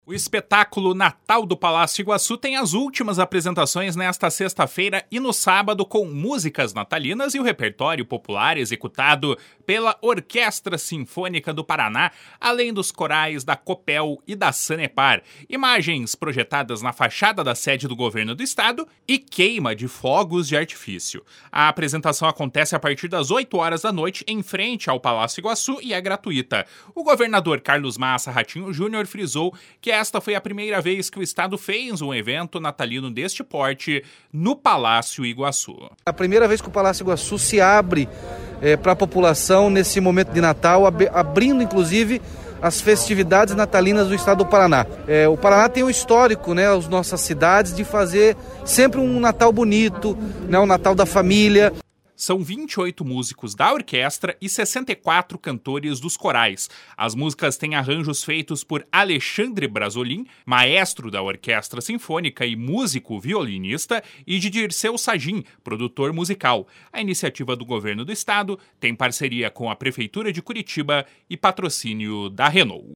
O governador Carlos Massa Ratinho Junior frisou que esta foi a primeira vez que o Estado fez um evento natalino deste porte no Palácio Iguaçu. // SONORA RATINHO JUNIOR //